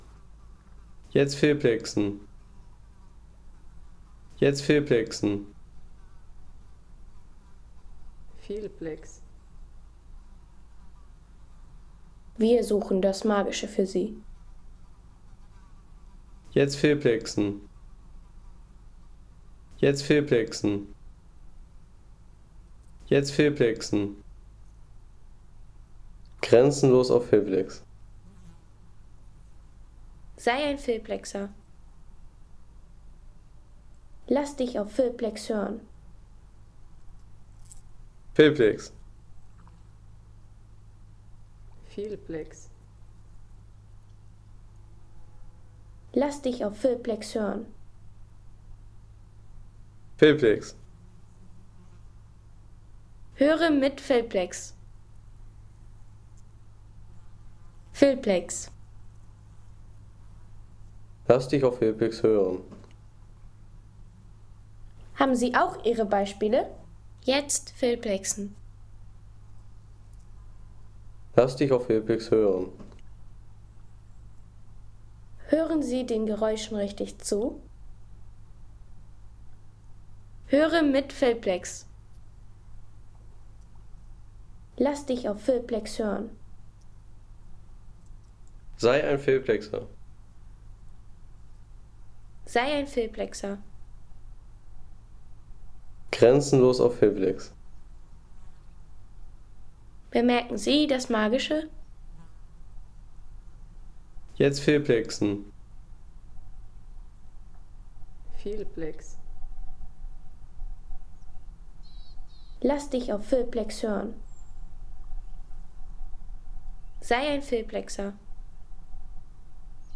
Magische Morgenstille am Byrtevatn | Ruhige Seeatmosphäre
Ruhige Seeatmosphäre vom Byrtevatn in Norwegen mit Vogelstimmen und sanfter Morgenstimmung.
Ein ruhiger norwegischer See-Sound mit Vogelstimmen, sanftem Wasser und frischer Morgenstimmung für Filme, Reisevideos, Dokus und Sound-Postkarten.